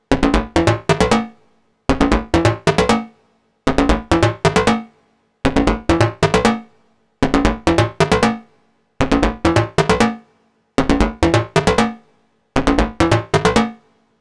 • Dm plucked modular synth techno cring sequenced 135.wav
Dm_plucked_modular_synth_techno_cring_sequenced_135_lYm.wav